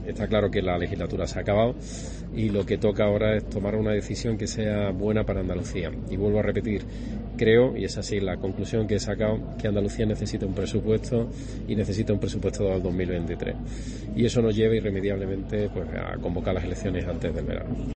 Este domingo durante la asistencia del presidente de la Junta de Andalucía, Juanma Moreno, a la romería de la Virgen de la Cabeza en Andújar (Jaén), se ha confirmado lo que se venía hablando desde una semana antes de la Semana Santa.